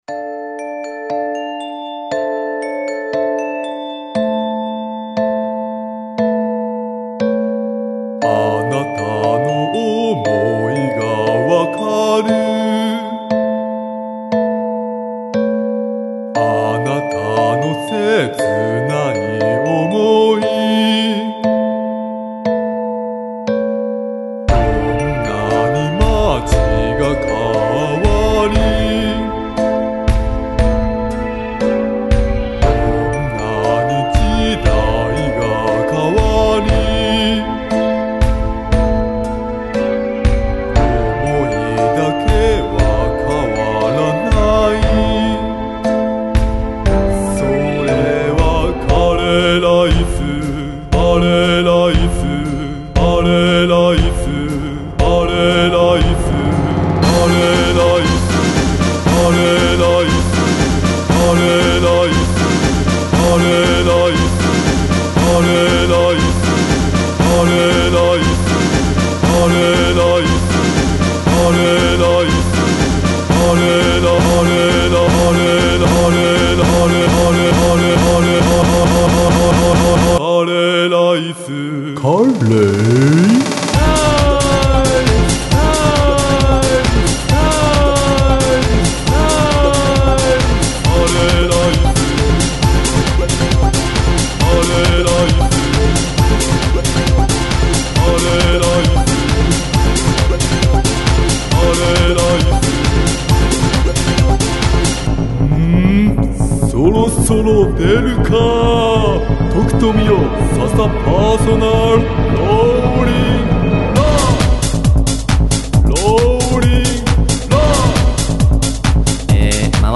ライブで踊れるようにアレンジ